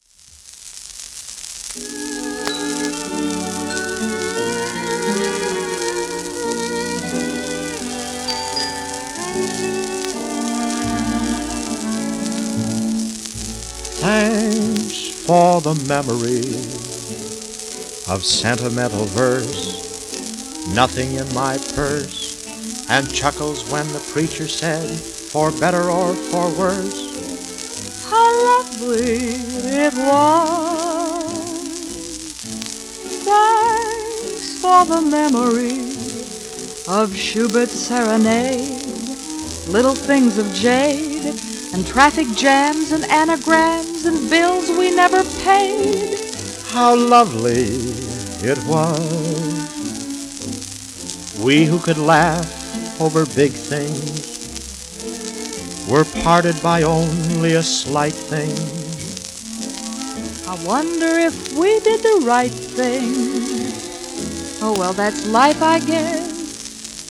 1938年のミュージカル映画ヒット曲